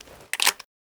Divergent / mods / Spas-12 Reanimation / gamedata / sounds / weapons / spas / load2.ogg